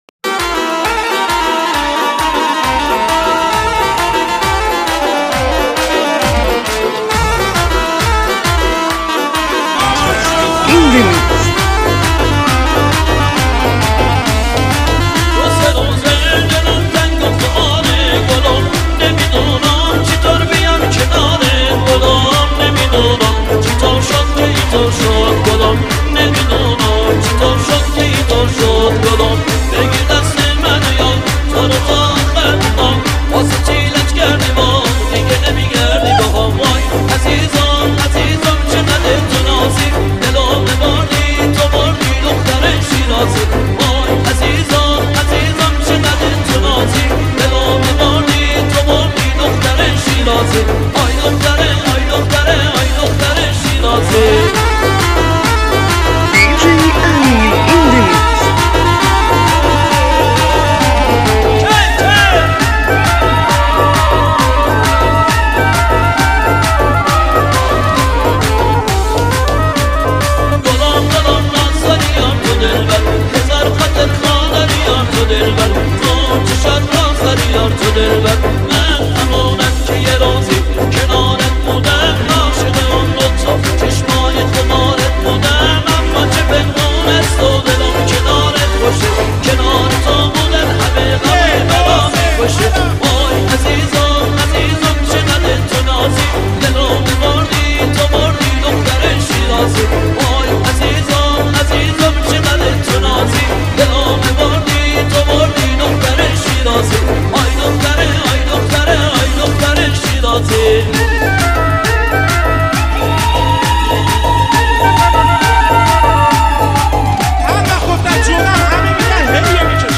دانلود ریمیکس جدید